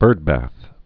(bûrdbăth, -bäth)